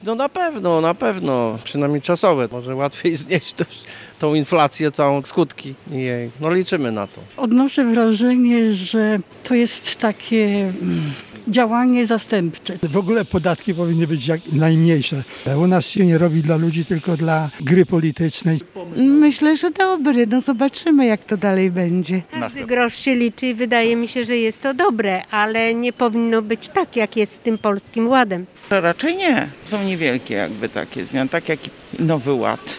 Zerowy VAT na żywność. Czy nasze zakupy będą tańsze? [SONDA]